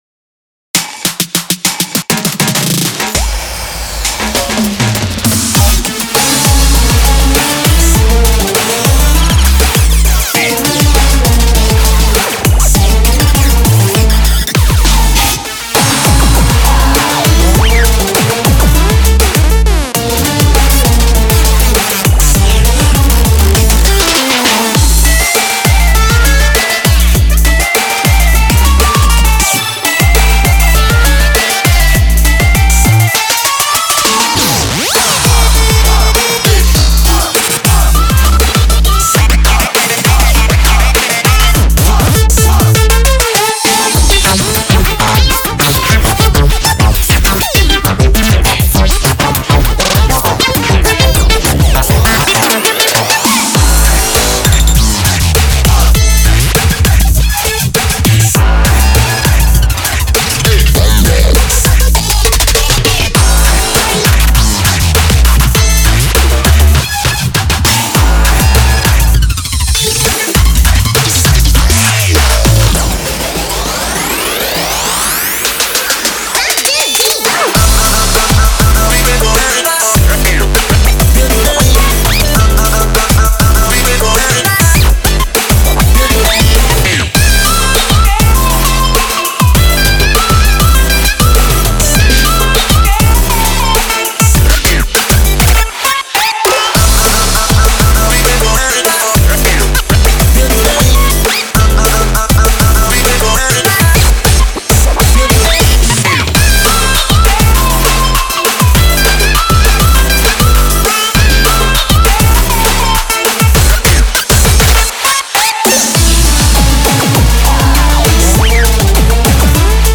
BPM200
Audio QualityPerfect (High Quality)
Genre: MIDDLE EASTERN TWERK.